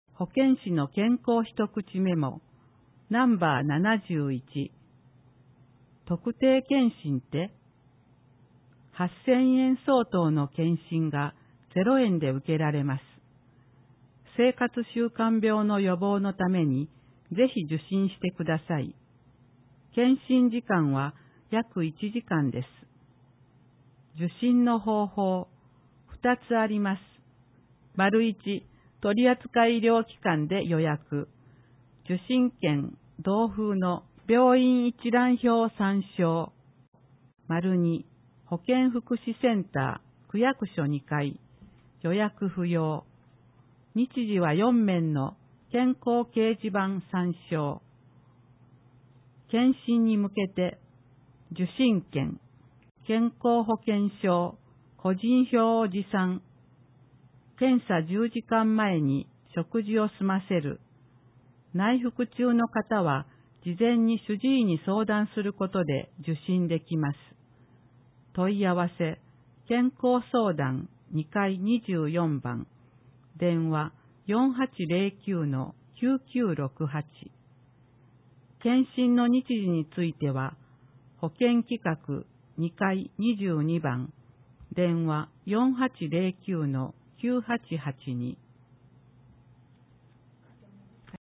広報ひがしよどがわ8月号 1面 音声版 広報ひがしよどがわ平成30年8月号(MP3形式, 164.65KB) 東淀川区役所は「前例がない」とは口にしません!!(MP3形式, 26.84KB) 区民まつりが新しくなる！